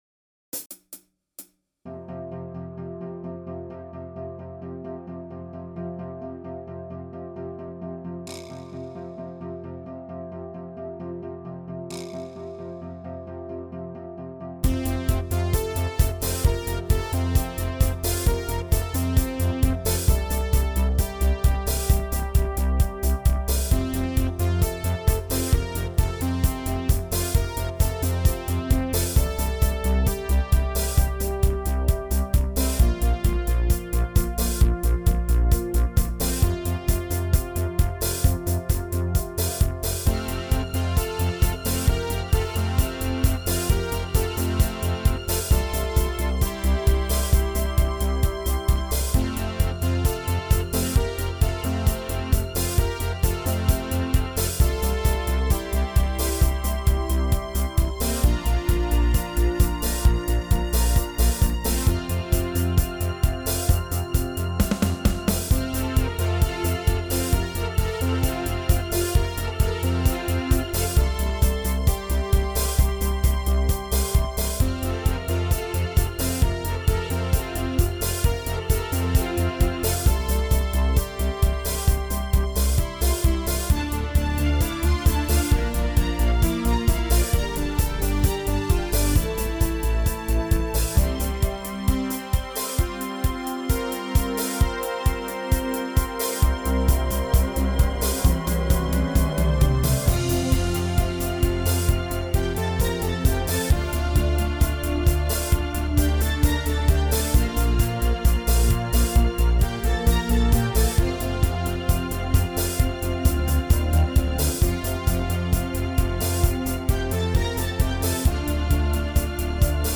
PRO MIDI INSTRUMENTAL VERSION